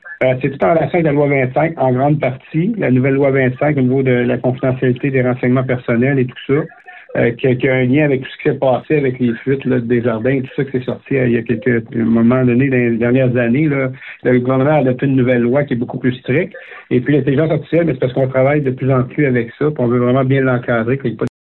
Le maire de Saint-Constant, Jean-Claude Boyer, indique qu’il est important de bien encadrer cette nouvelle technologie.